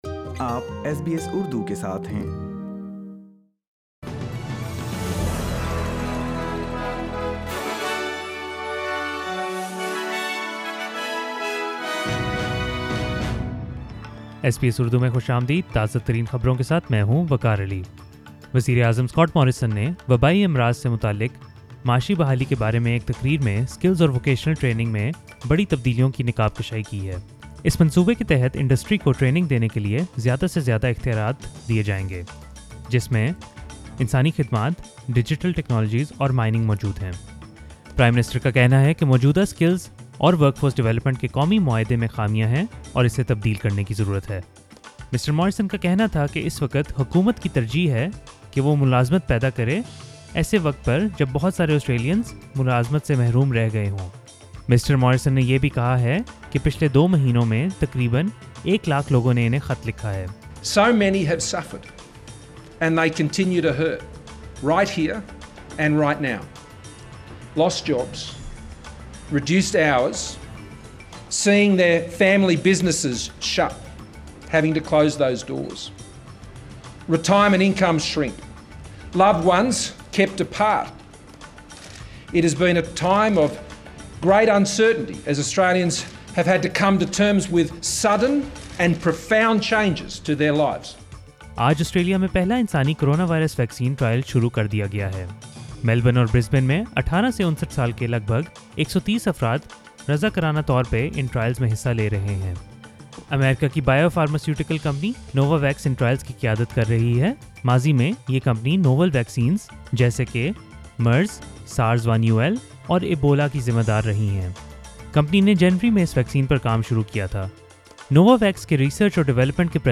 SBS Urdu News 26 May 2020